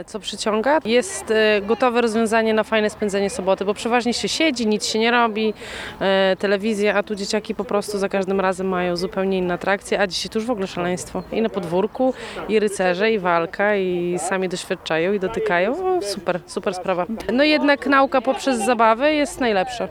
A co o przesądza o powodzeniu Sobotnich Poranków z Muzeum? Wiedzą rodzice cyklicznie uczestniczący w tej inicjatywie.